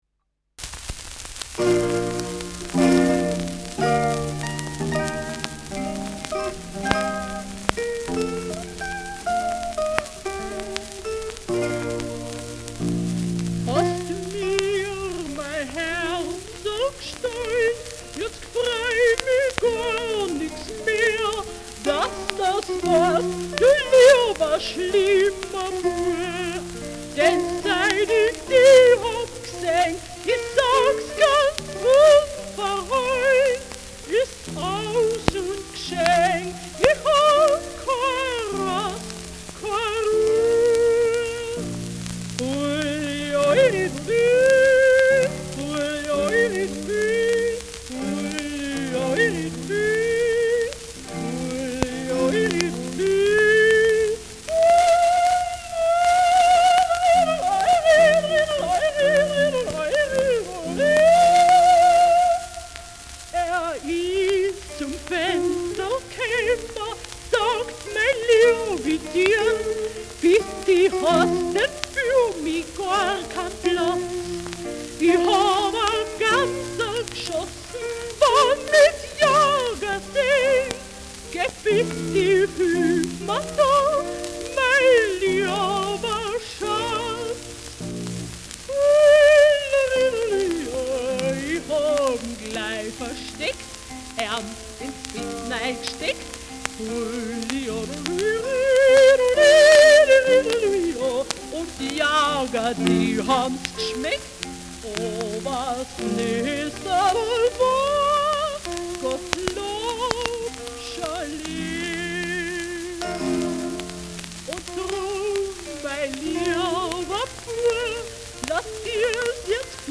mit Zitherbegleitung
Schellackplatte
Stubenmusik* FVS-00016